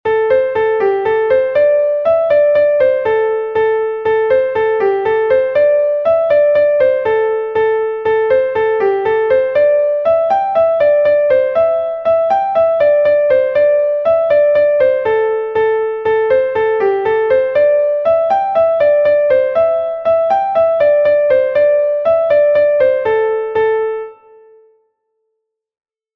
Gavotte de Bretagne